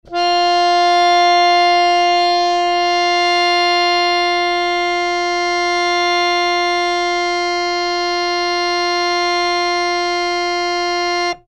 interactive-fretboard / samples / harmonium / F4.mp3